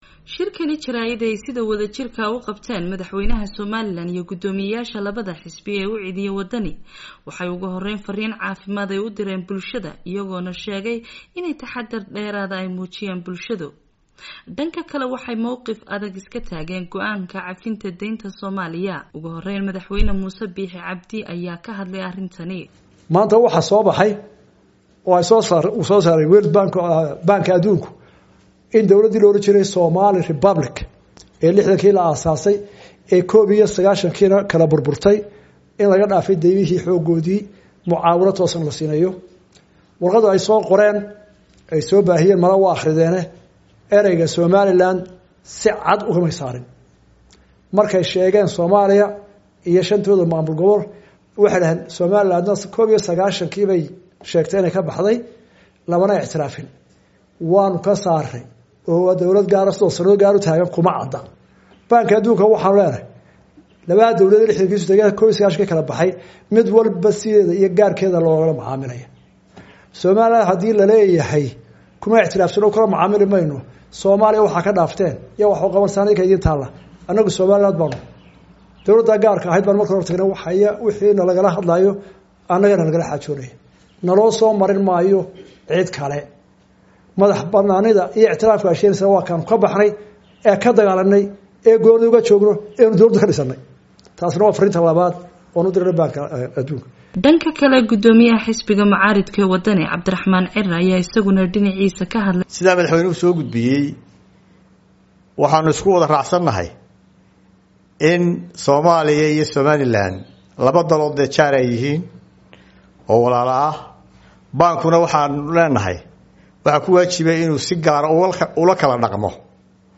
Warbixintan waxaa Hargeysa ka soo dirtay